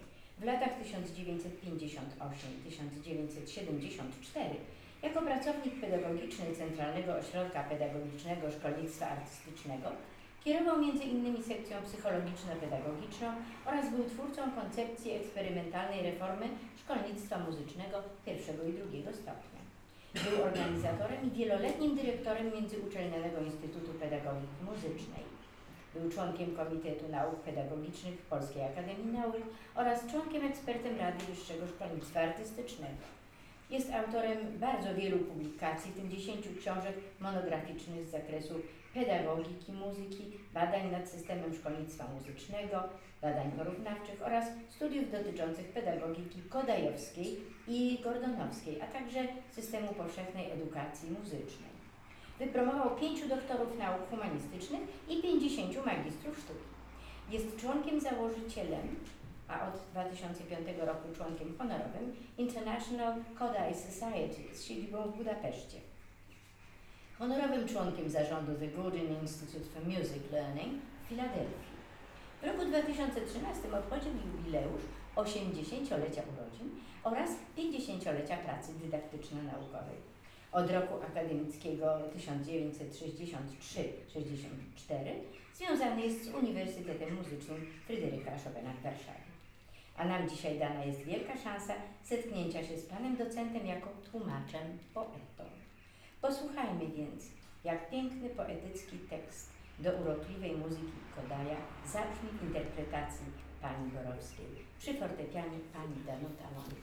W Bibliotece Publicznej im. Marii Konopnickiej, w Sali im. Jadwigi Towarnickiej w niedzielny wieczór (23 września) miał miejsce  II Koncert Kameralny.